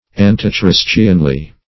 Antichristianly \An`ti*chris"tian*ly\, adv. In an antichristian manner.